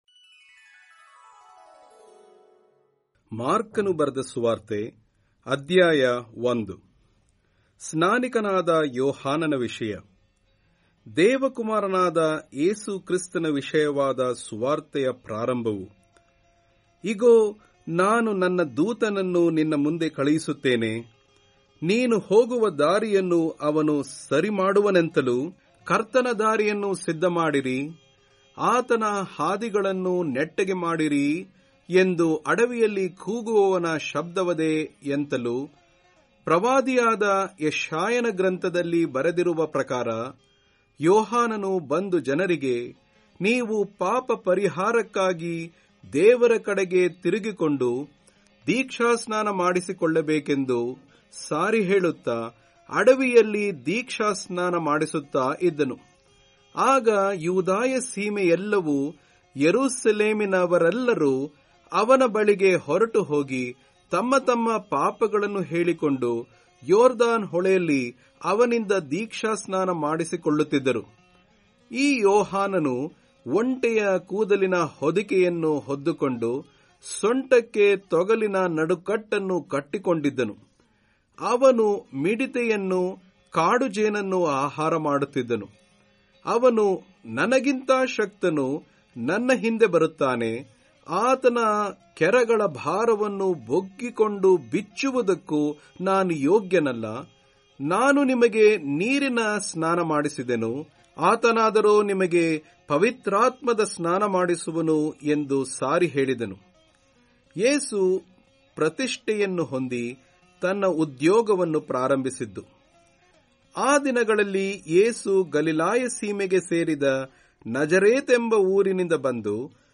• Word for word narration
• Voice only Bible reading
kannada-bible-9686-mark-1.mp3